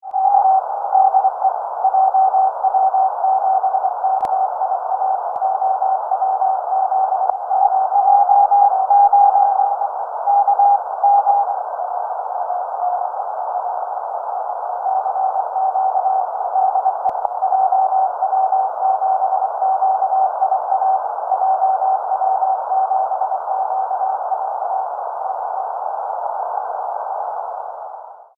Here is a recording, just after I made the QSO.
As you can hear, the signal was not very strong and the CQ’s were unanswered.